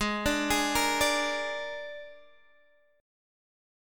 G#sus2b5 Chord